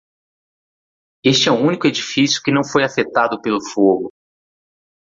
Pronunciado como (IPA) /a.feˈta.du/